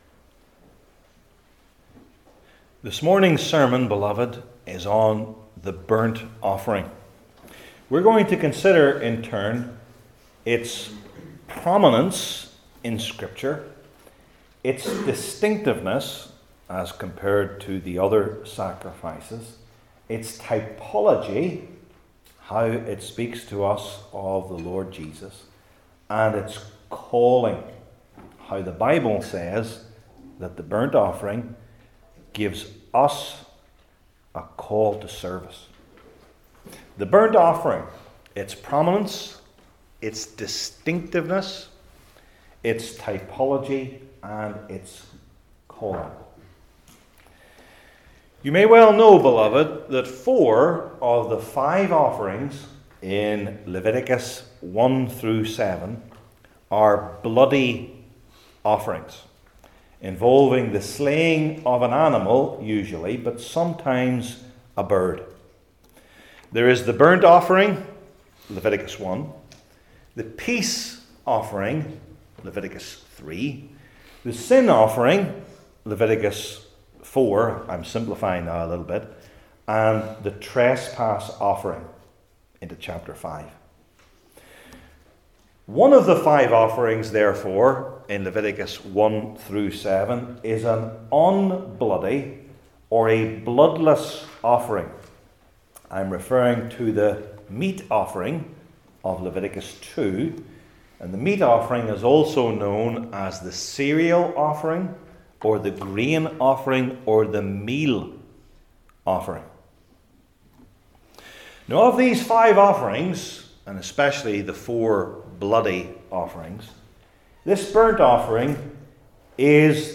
Leviticus 1 Service Type: Old Testament Sermon Series I. Its Prominence II.